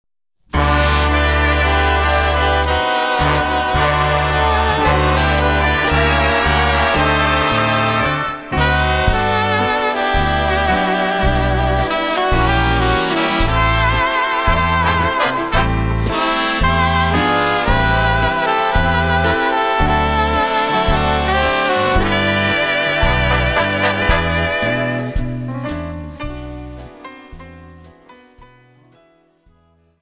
FOX-TROT:28(4+64)